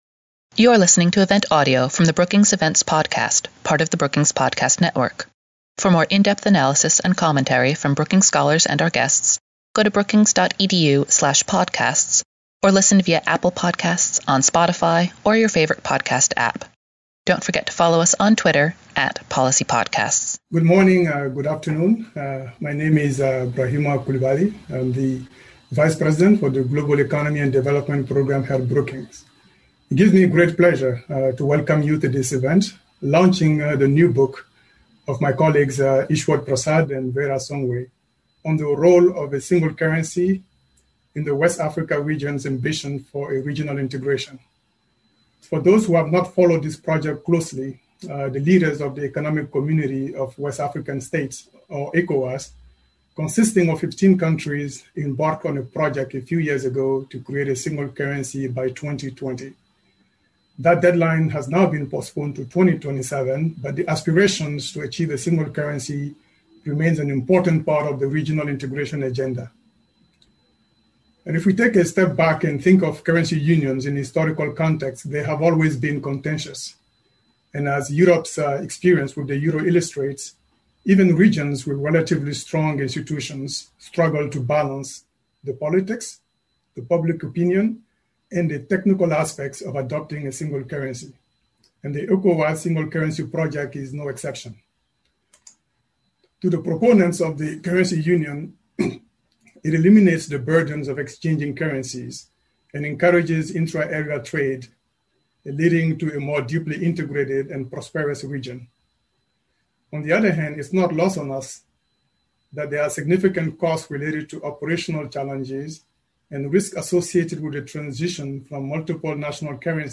Panelists explored whether the countries involved are ready for the effort, which obstacles should be addressed so that the currency can be successful, and alternative paths to enhanced economic integration on the continent. After the program, the panelists took audience questions.